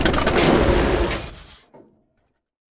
tank-brakes-2.ogg